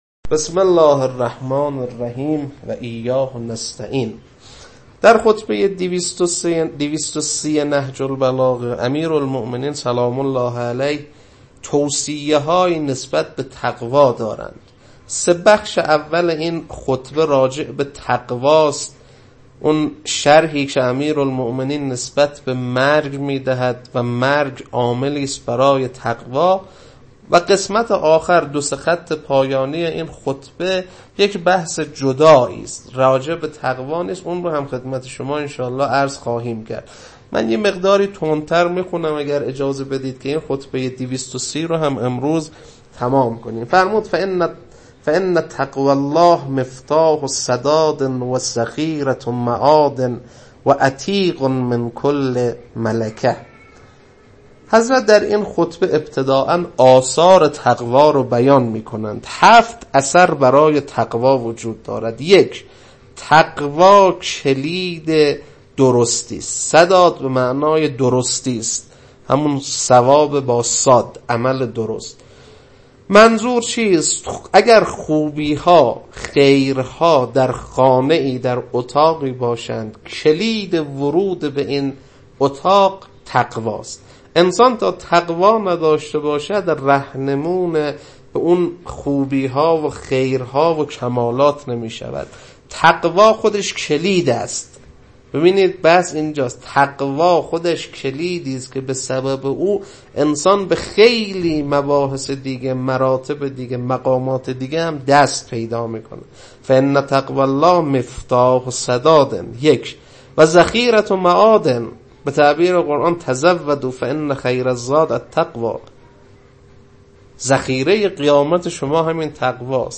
خطبه-230.mp3